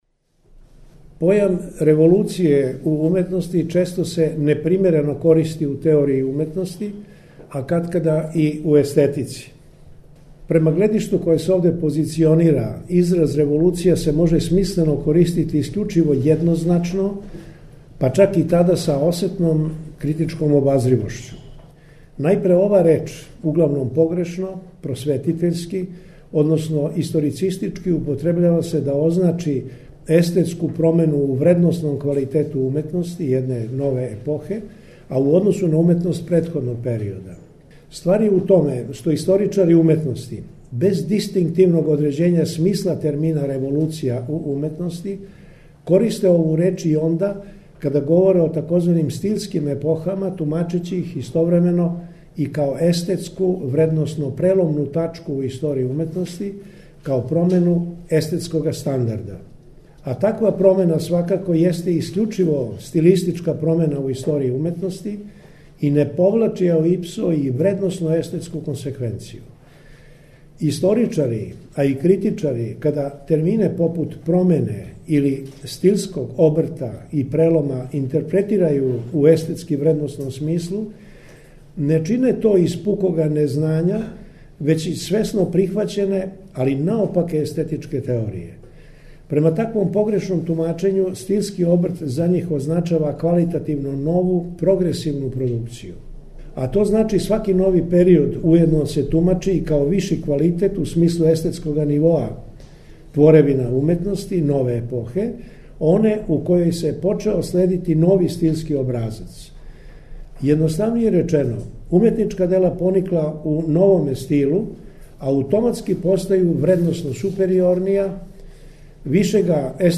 Научни скупoви
преузми : 10.94 MB Трибине и Научни скупови Autor: Редакција Преносимо излагања са научних конференција и трибина.